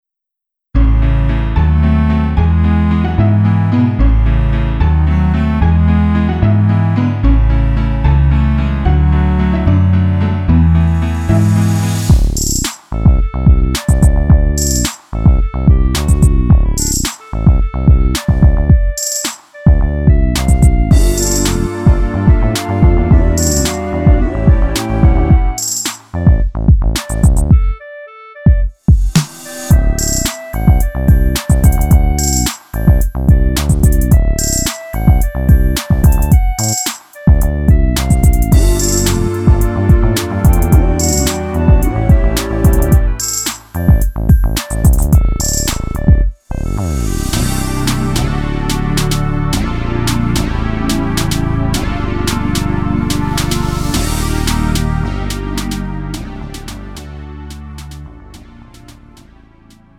음정 원키 3:11
장르 가요 구분 Lite MR